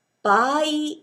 a 場合（ばあい）＝　Case, Circumstance
baai.mp3